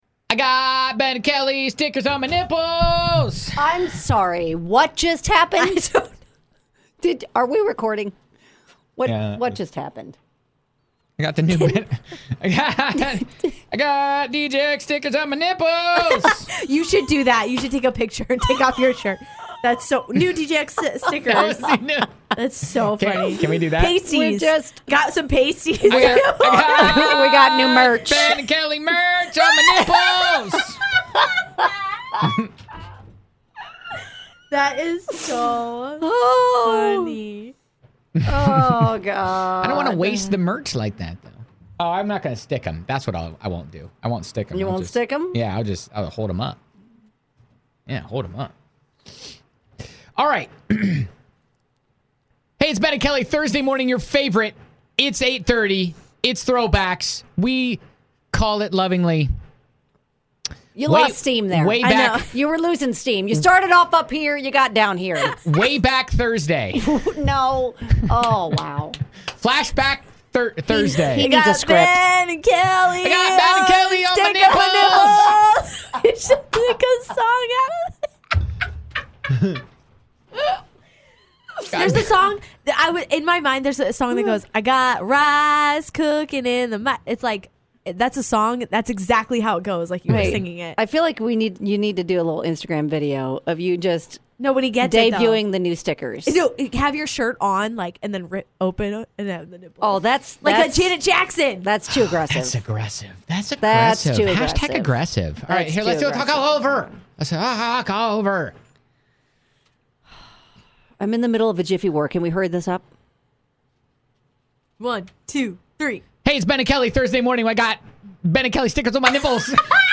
Here's a small look at the show cutting a promo for Thursday's show... includes a reference to new morning show stickers.